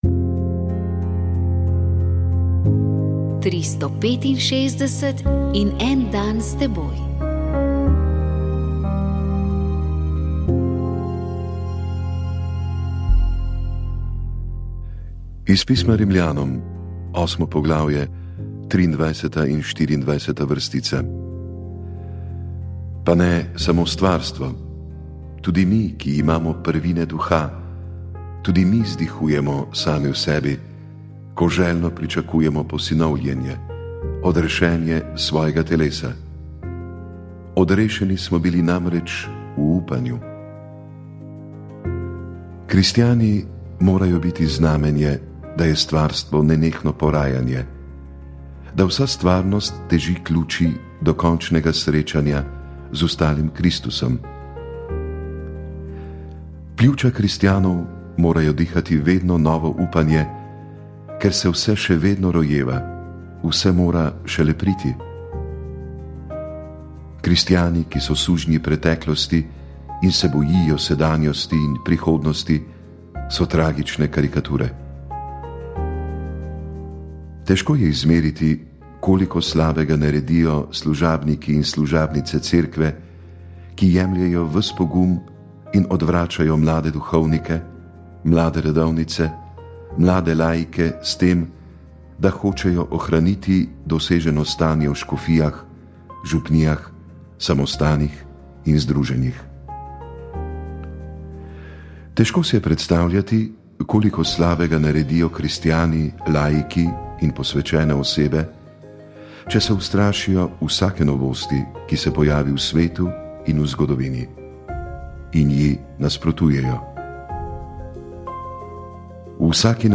Kdaj pomisliti na to, da gre lahko za pljučno hipertenzijo in kakšne so možnosti zdravljenja? Naša gostja je bila specialistka interne medicine